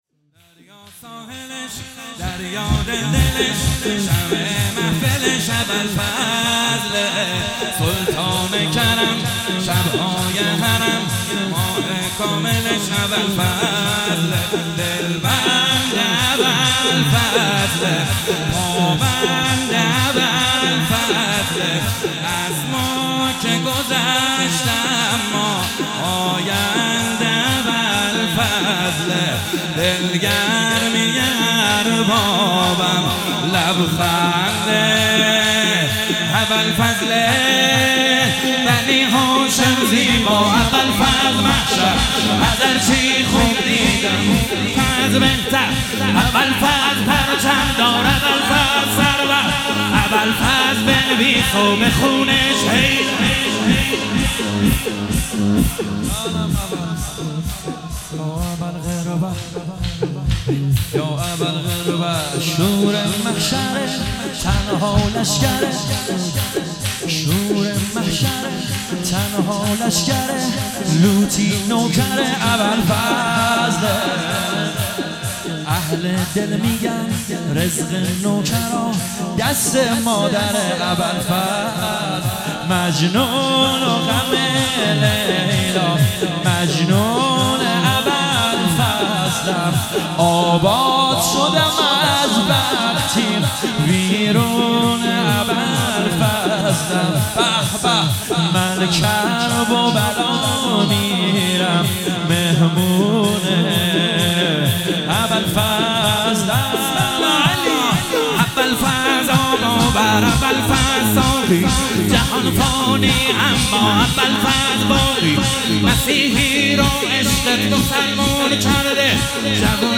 فاطمیه